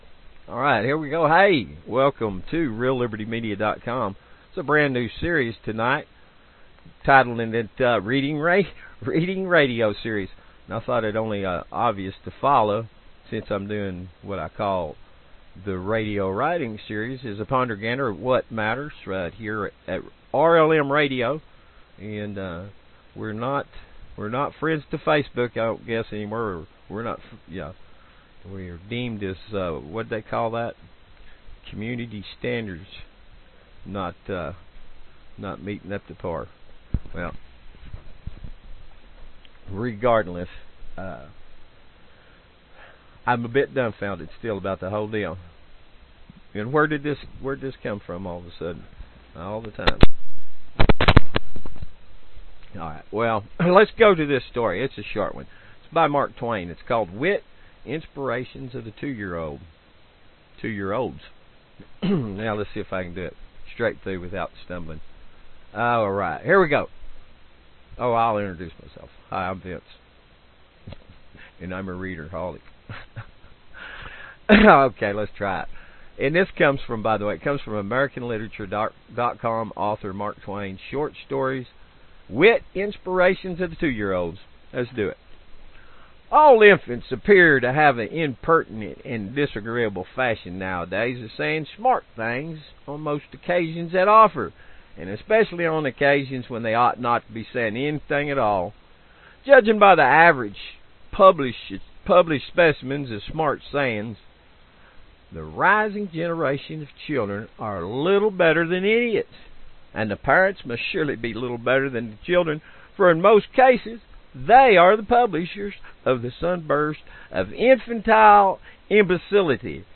Genre Radio Reading